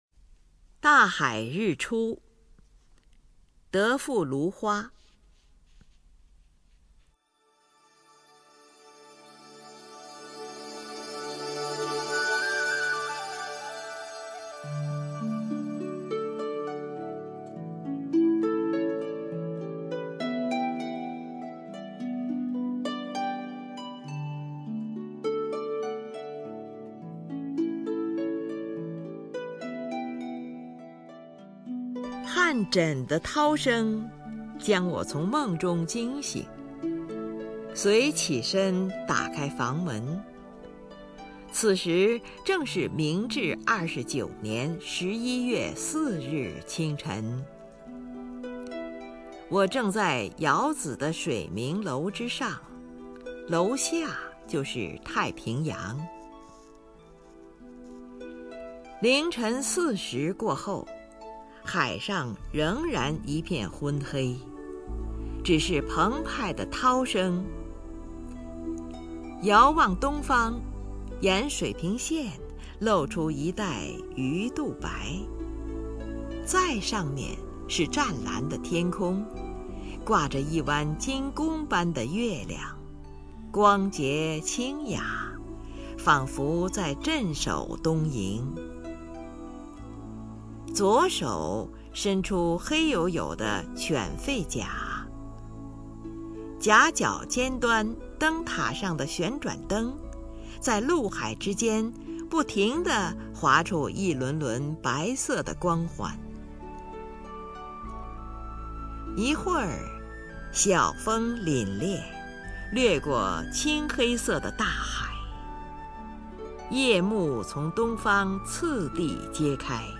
林如朗诵：《大海日出》(（日）德富芦花)　/ （日）德富芦花
名家朗诵欣赏 林如 目录